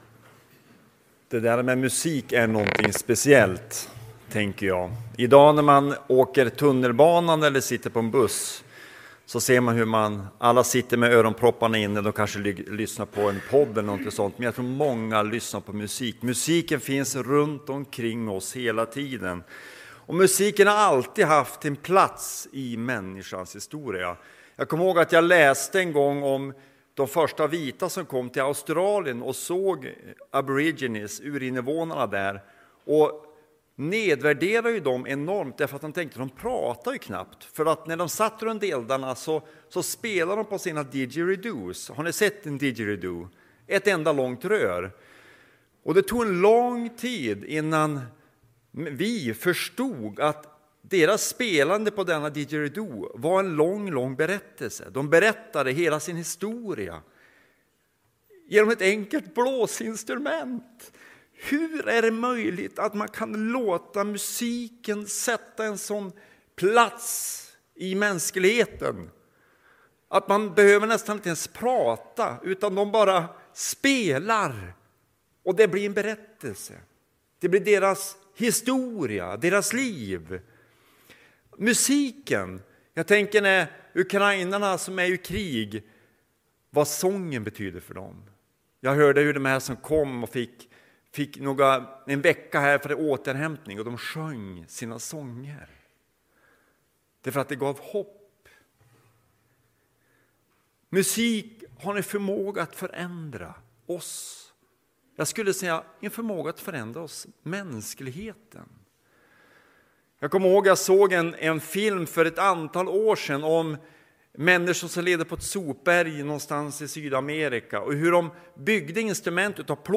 predikar